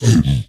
Minecraft Version Minecraft Version 25w18a Latest Release | Latest Snapshot 25w18a / assets / minecraft / sounds / mob / zombified_piglin / zpigangry2.ogg Compare With Compare With Latest Release | Latest Snapshot
zpigangry2.ogg